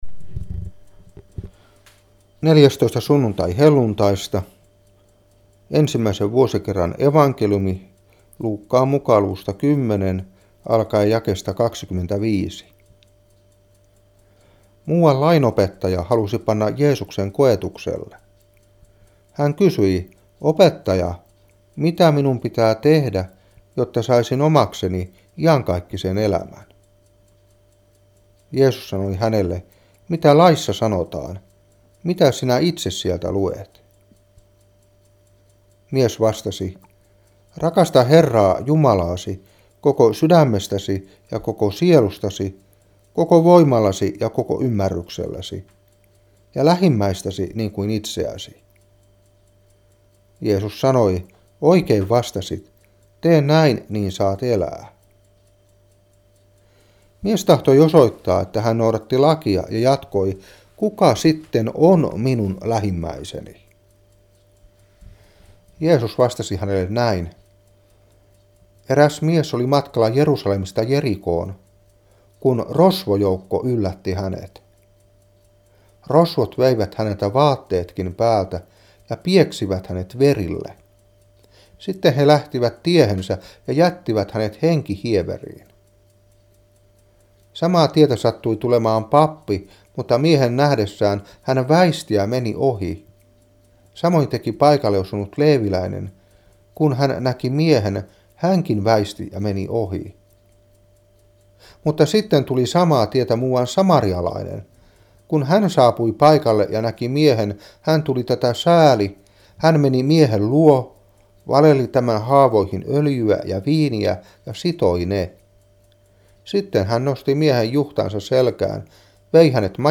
Saarna 2016-8. Luuk.10:25-37.